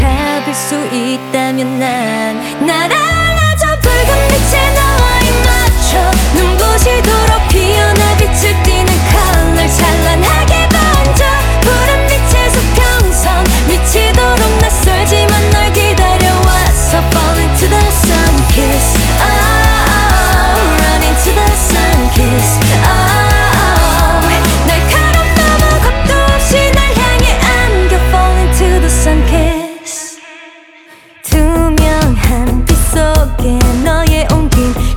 Жанр: Поп музыка / Рок
Rock, Pop, K-Pop